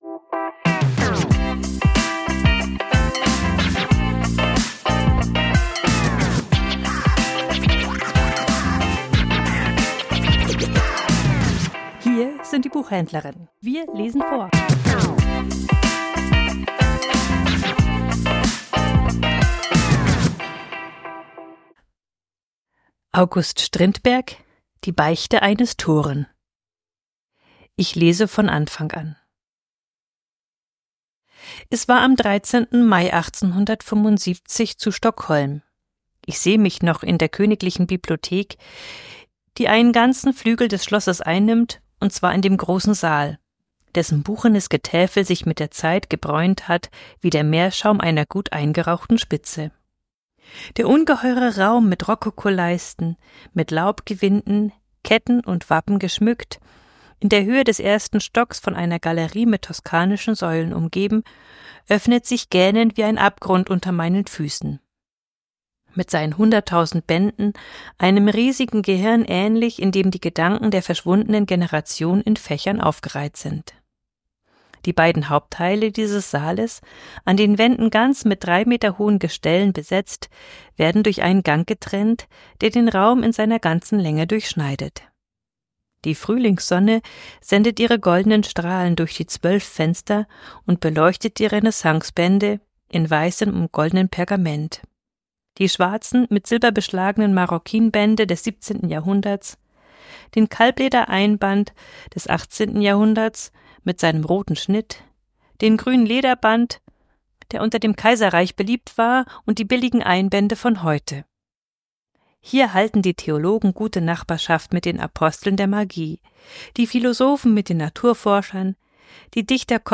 Vorgelesen: Die Beichte eines Toren ~ Die Buchhändlerinnen Podcast